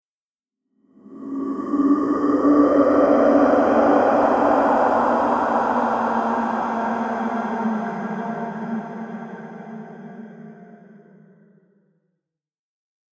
Download Scary Horror sound effect for free.
Scary Horror